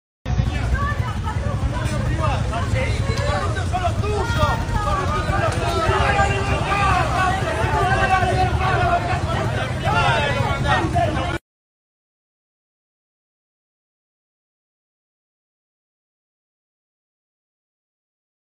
Durante la llegada de Milei a su caravana en Lomas de Zamora, una kirchnerista le gritó "chorro corrupto" y el presidente la domó: "¡Corrupto son los tuyos!".